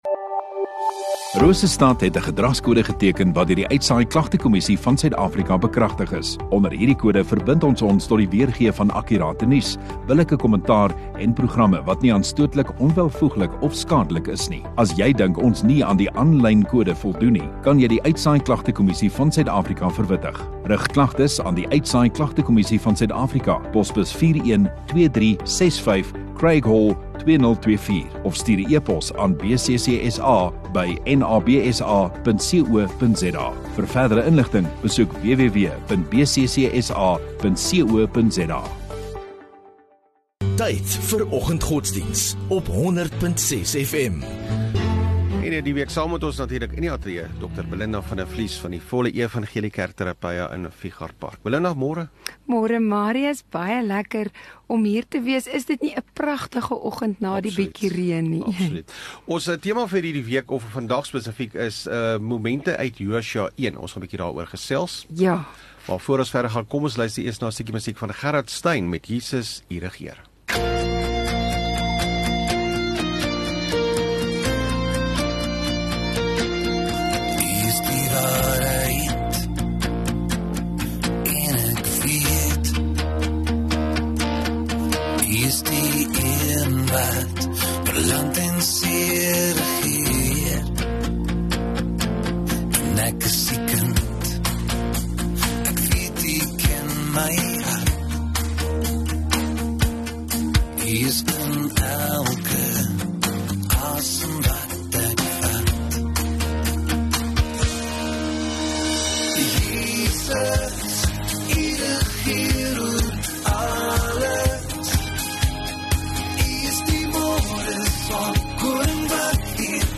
26 Nov Maandag Oggenddiens